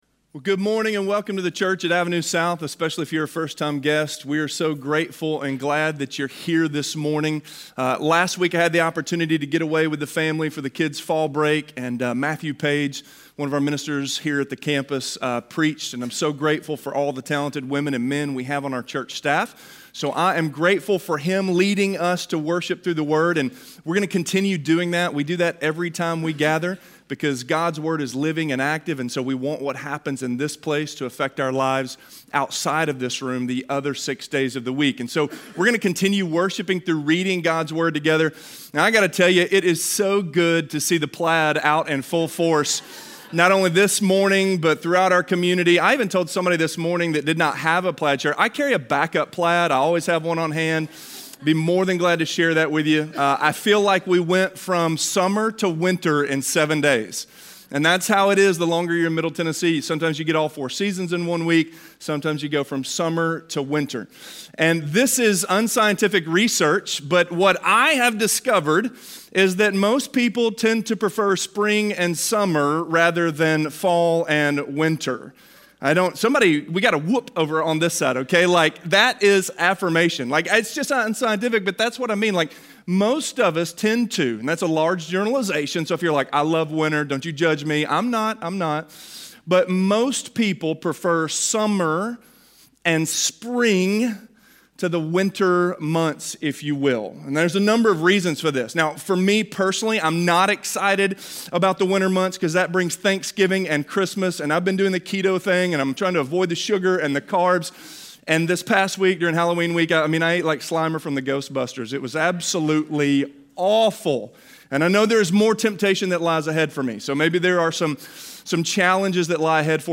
A Time To Let Go - Sermon - Avenue South